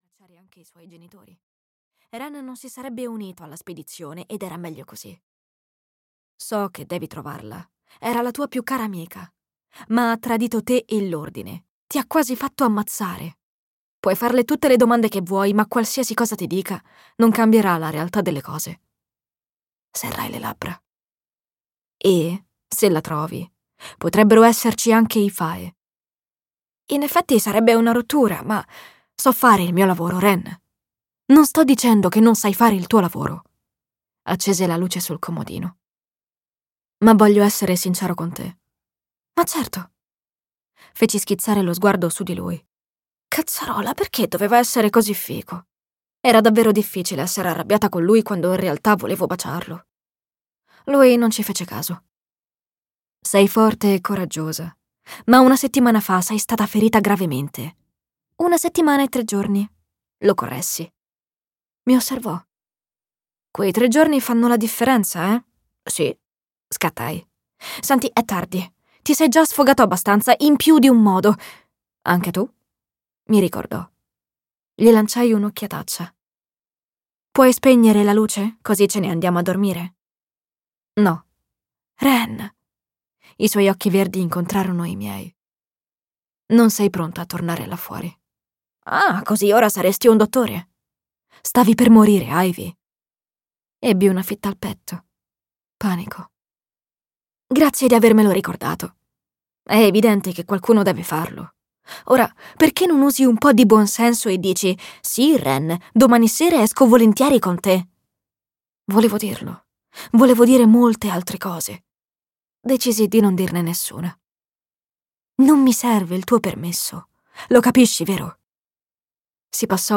Ritorno da te" di Jennifer L. Armentrout - Audiolibro digitale - AUDIOLIBRI LIQUIDI - Il Libraio